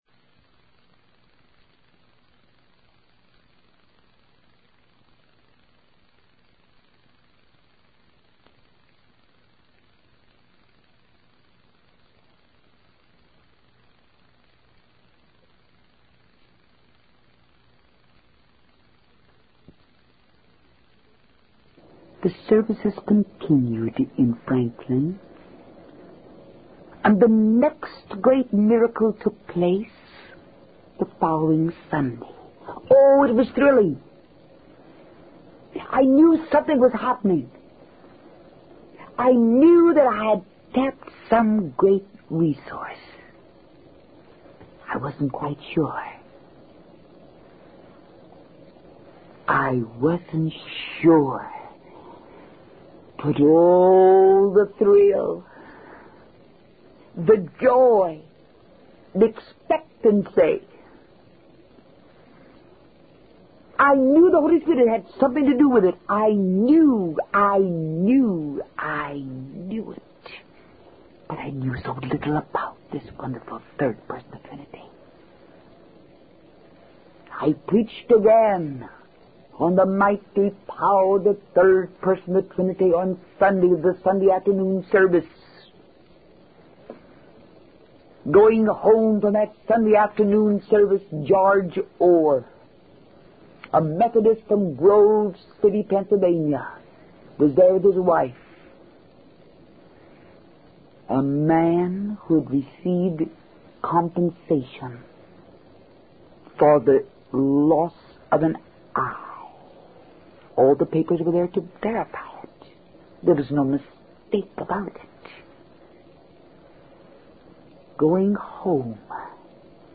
In this sermon, the preacher emphasizes the importance of seeking the secret to salvation in God's Word.